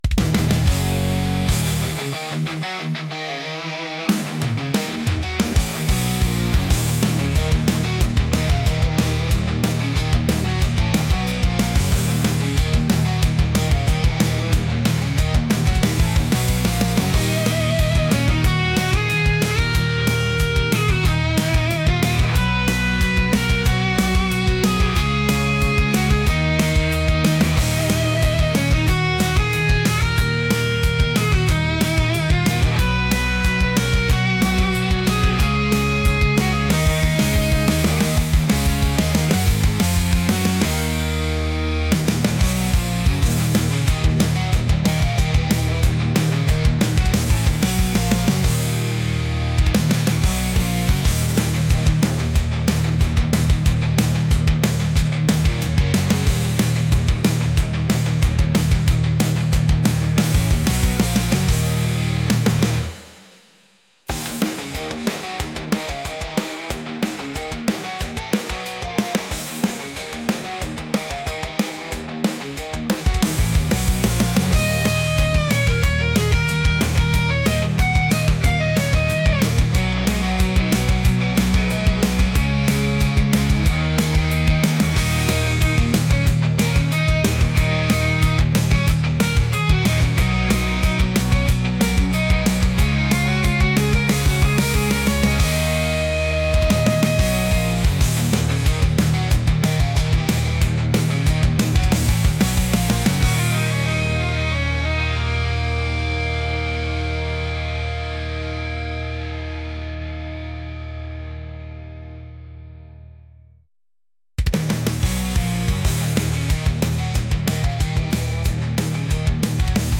energetic | heavy | rock